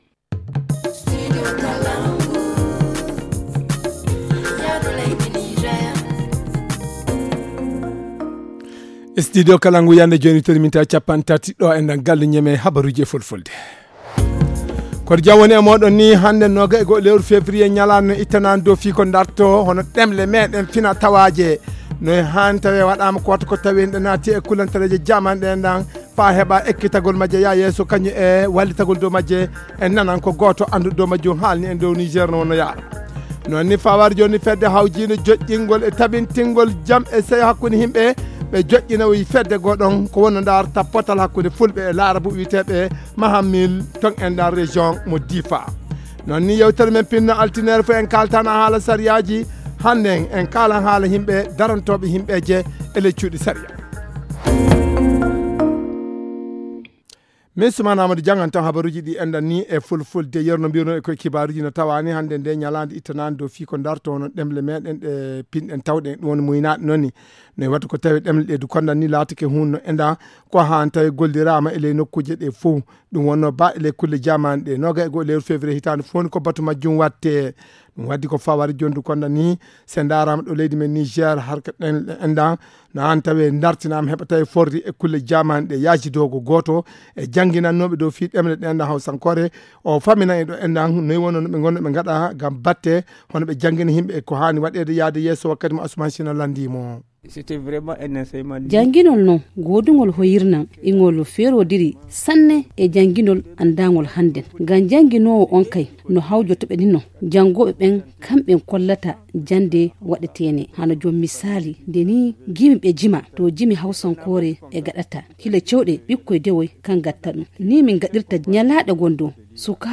Le journal du 21 février 2022 - Studio Kalangou - Au rythme du Niger